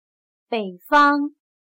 北方/běifāng/norte